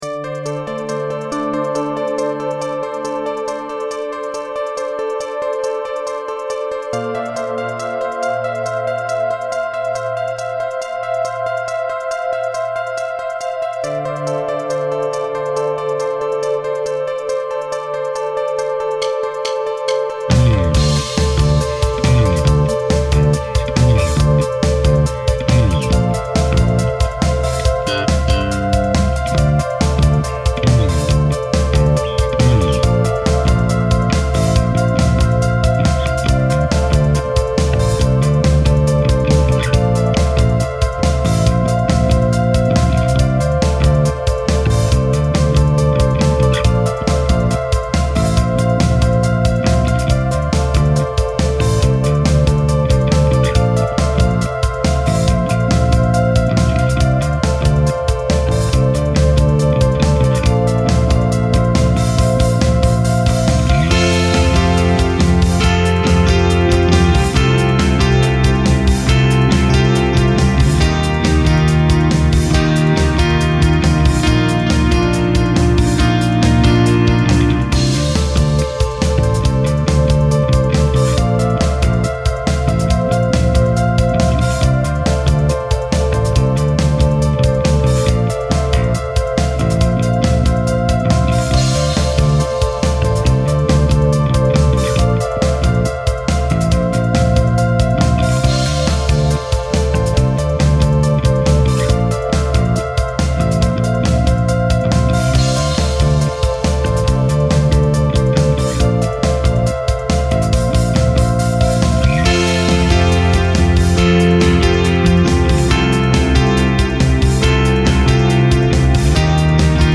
BPM : 139